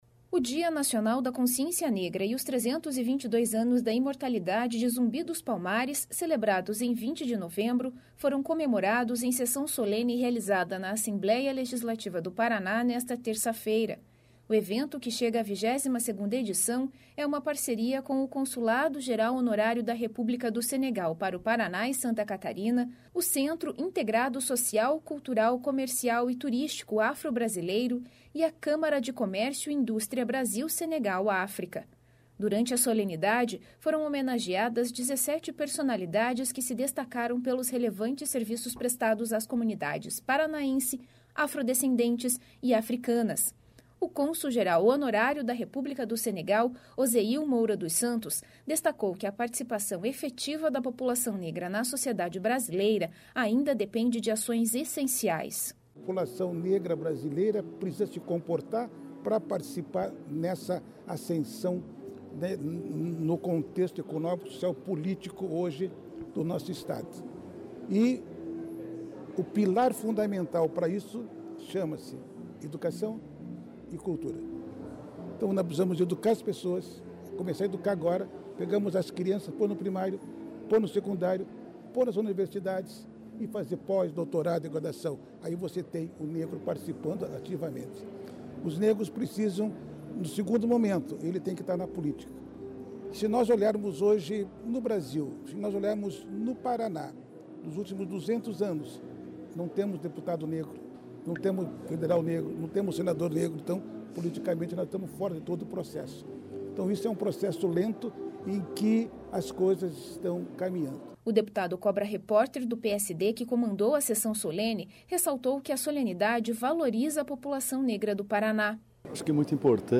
Solenidade celebra o Dia da Consciência Negra e a memória de Zumbi dos Palmares